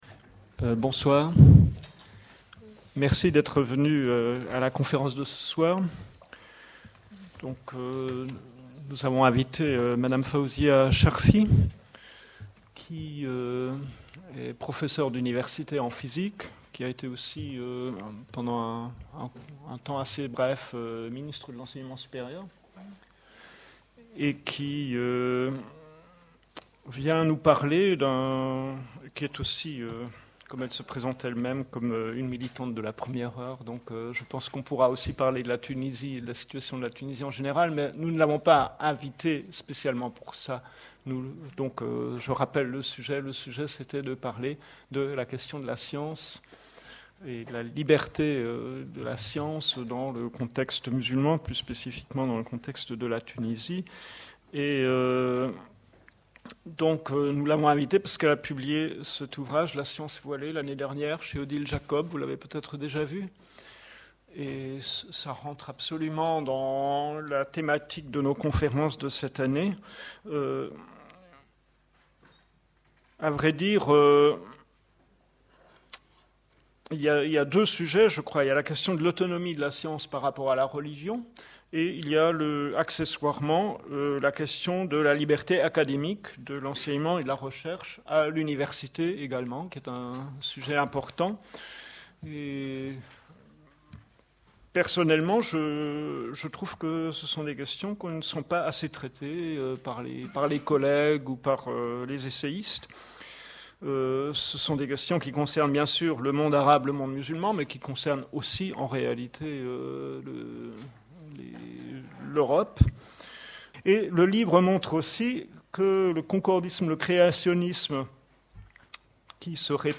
Conférence Faouzia Farida Charfi, professeure de physique à l'Université de Tunis, secrétaire d'État à l'Enseignement supérieur